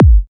VEC3 Bassdrums Trance 38.wav